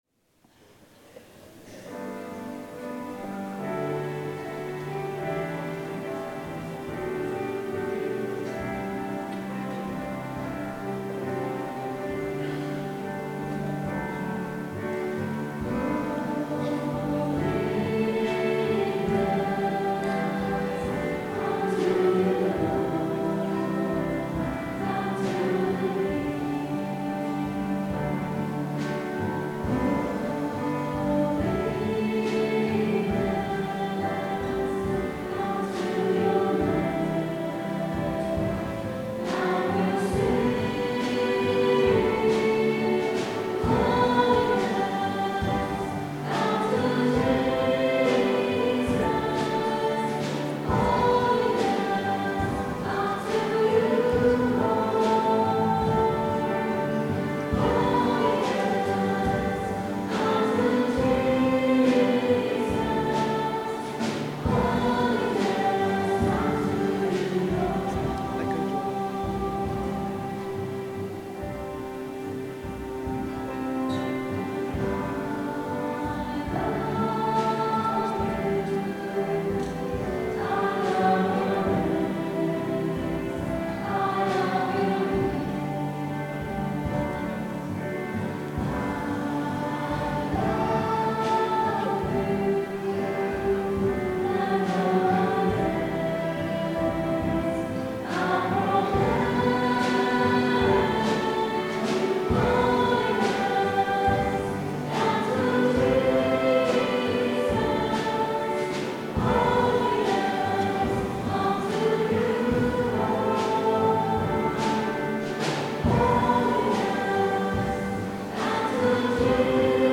Recorded on Sony Minidisc in digital stereo at Easter Sunday mass at 10am on 23rd March 2008.